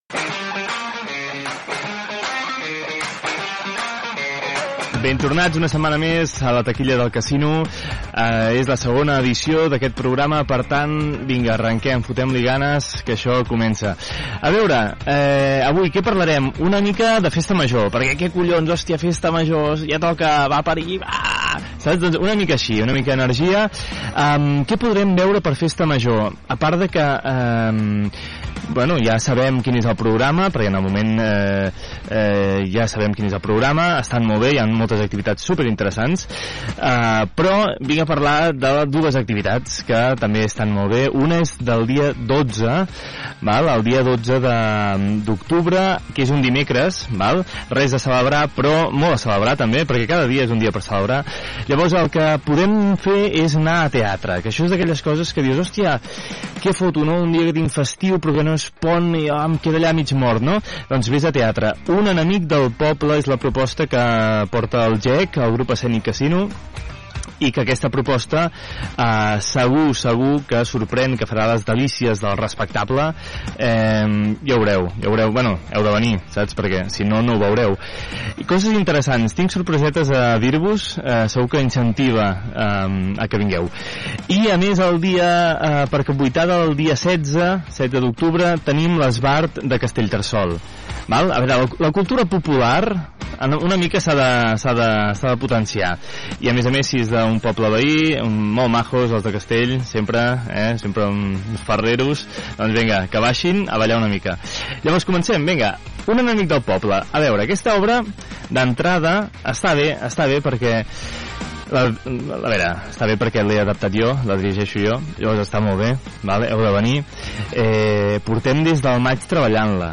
FM
Fragment extret del web de Ràdio Caldes.